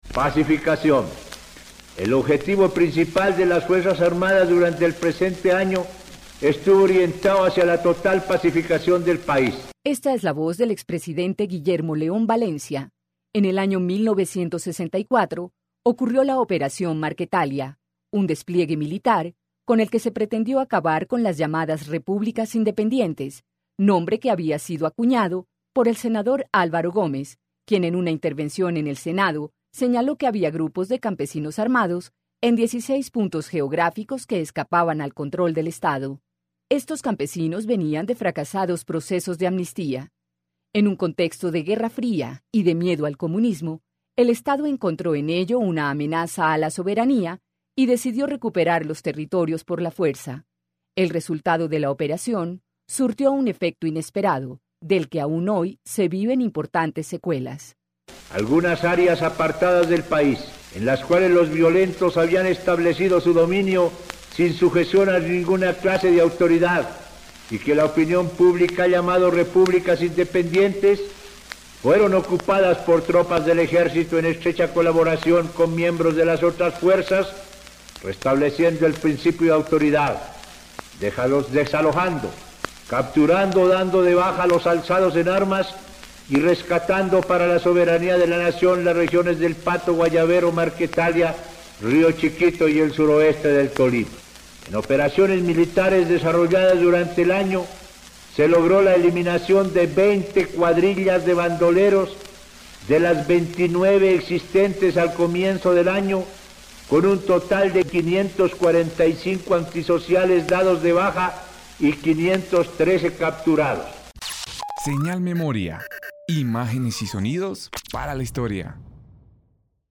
Audio radial: